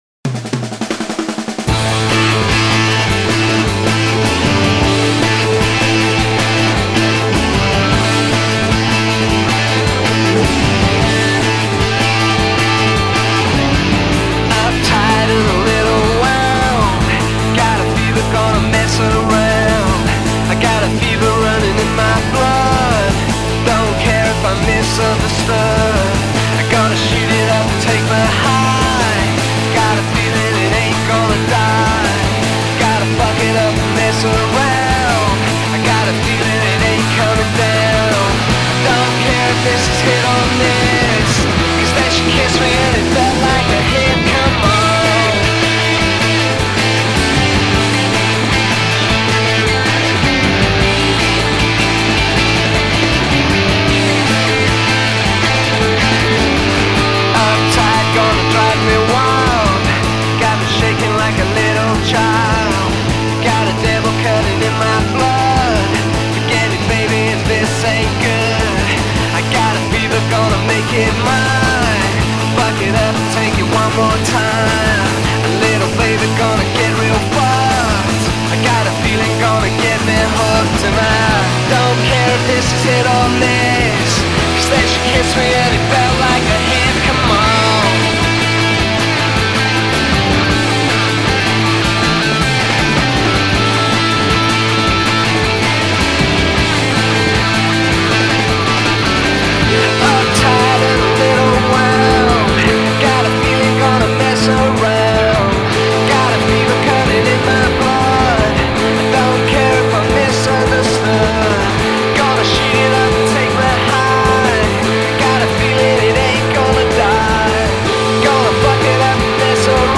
60’s-influenced druggy